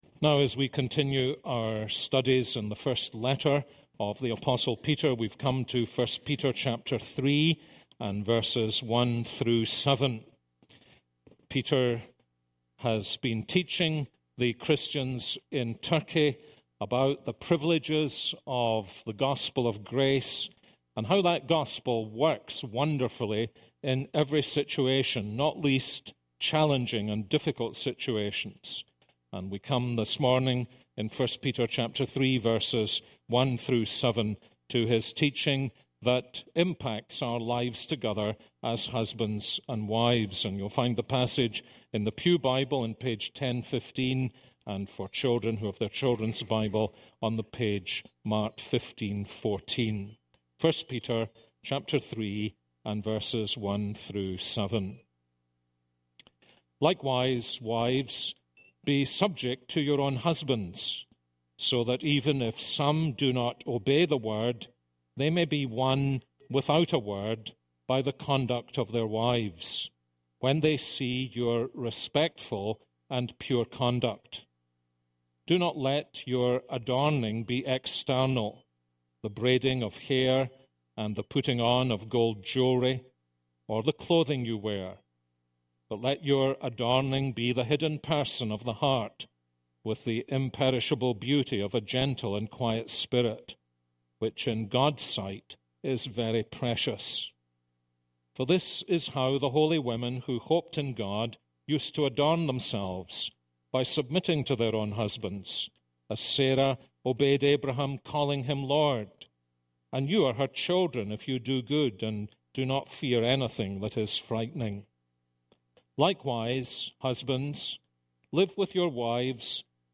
This is a sermon on 1 Peter 3:1-7.